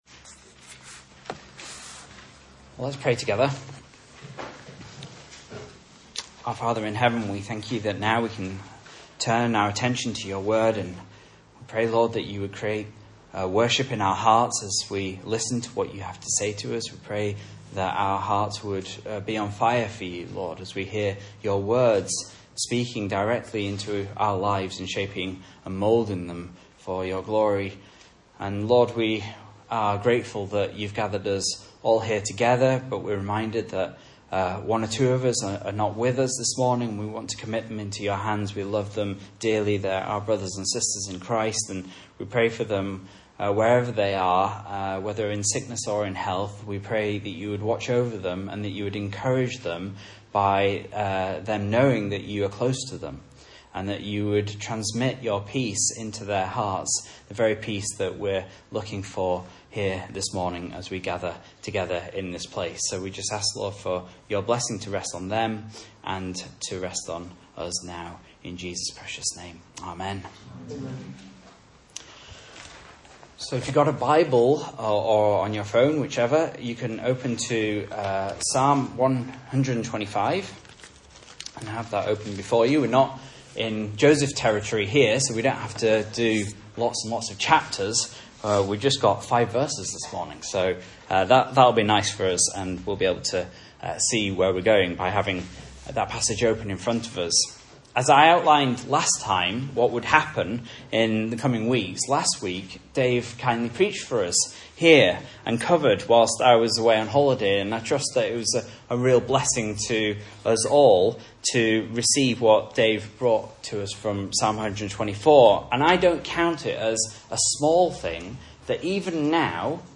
Message Scripture: Psalm 125 | Listen